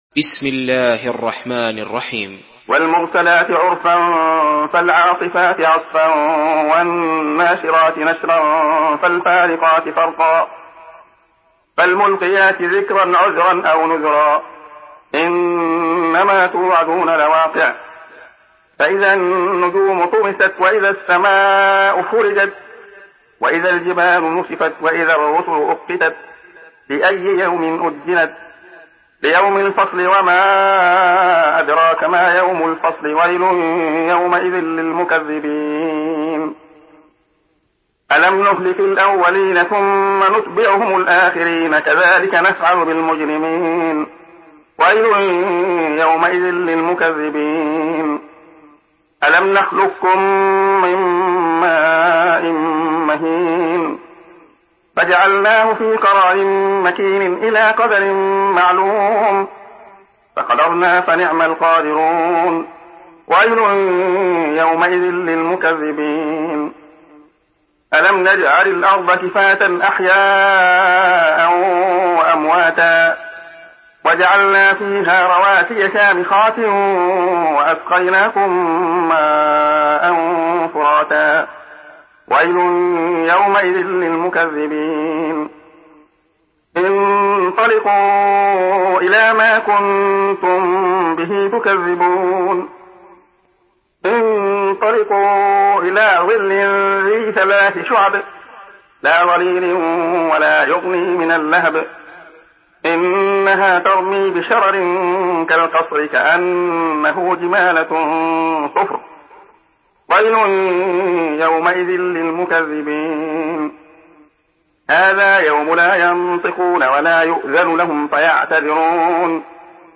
Surah আল-মুরসালাত MP3 by Abdullah Khayyat in Hafs An Asim narration.